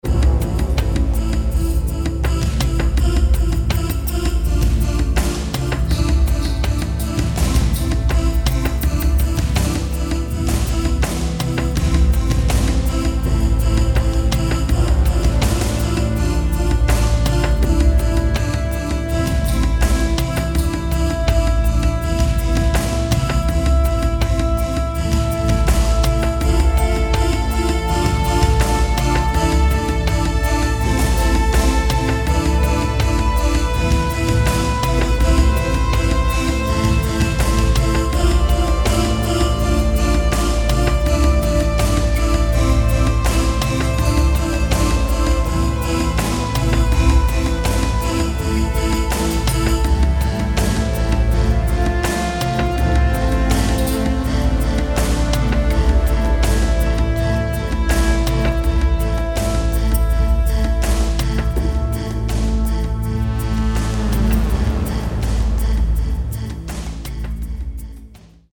Buoyant, dramatic and heartfelt